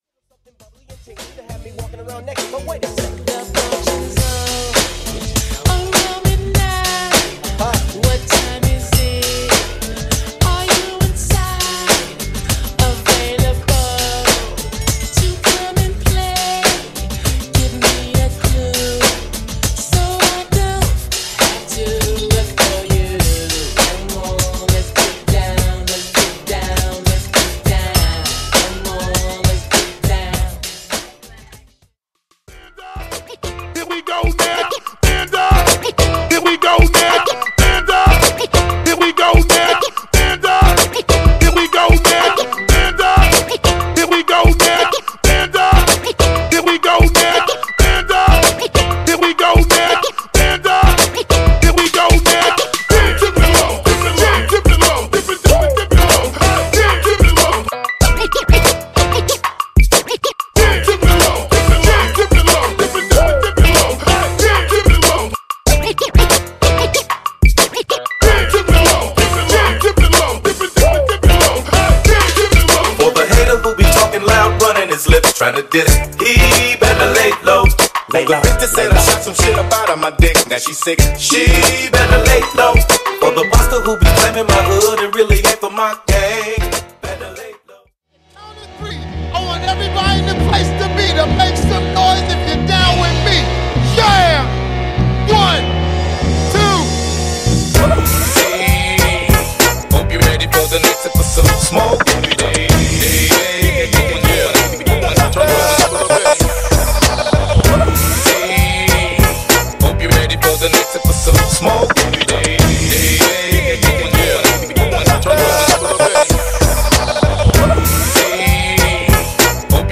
(Old School Edition)